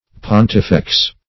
Search Result for " pontifex" : Wordnet 3.0 NOUN (1) 1. a member of the highest council of priests in ancient Rome ; The Collaborative International Dictionary of English v.0.48: Pontifex \Pon"ti*fex\, n.; pl.